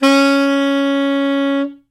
Звуки саксофона
Запись саксофона с гулкими гудками